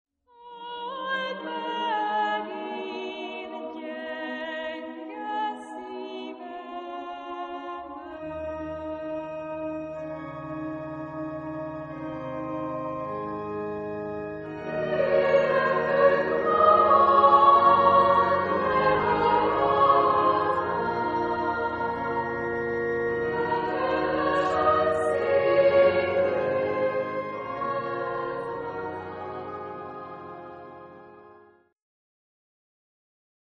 Genre-Style-Form: Christmas carol
Mood of the piece: mystical ; moderate
Type of Choir: SAA  (3 children OR women voices )
Tonality: D dorian